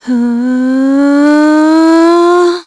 Isolet-Vox_Casting2.wav